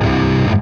terraria_axe.wav